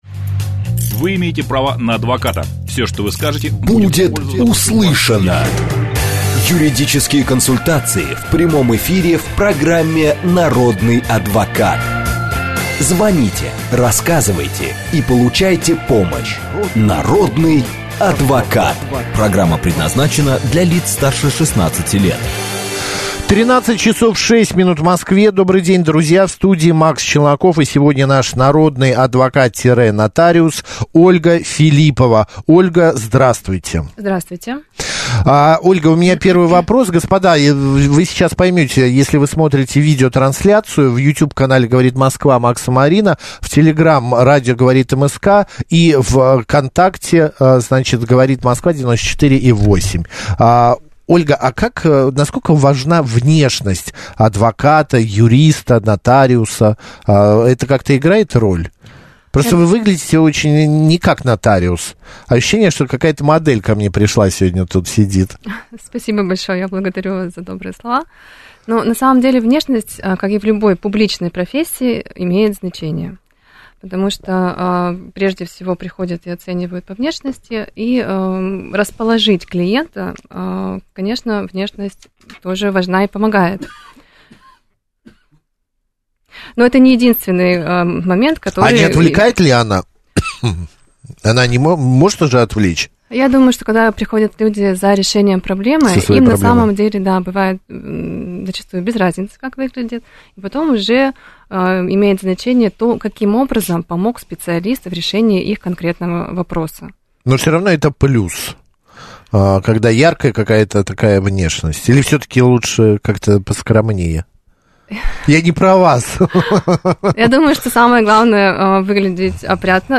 Новость: Нотариус в радиоэфире: напишите завещание и живите спокойно
На первый взгляд простой вопрос от радиослушателя имеет подводные камни. Нотариус отметила, что оформить дарственную на долю квартиры возможно только в нотариальной форме.
Слушательница спросила, можно ли изменить завещание? Нотариус ответила, что можно составить новое завещание, при этом предыдущее станет недействительным.